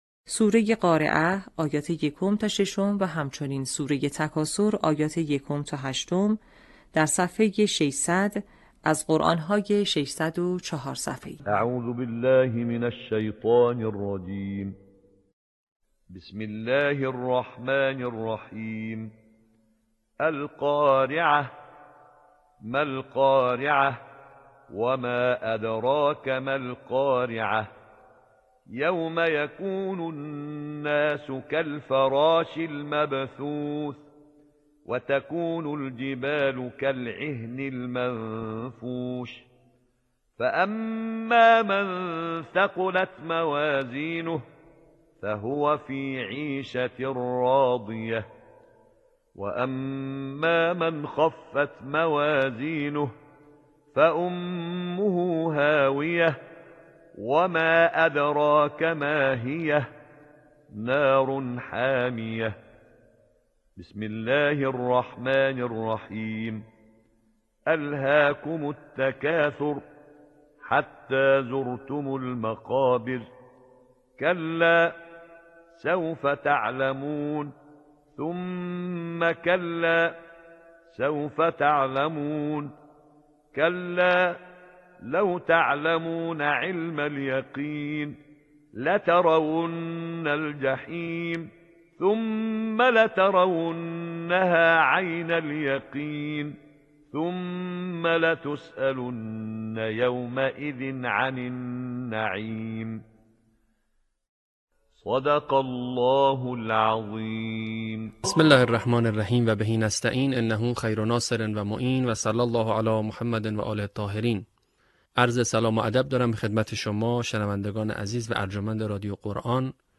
صوت | آموزش حفظ جزء ۳۰، سوره‌های قارعه و تکاثر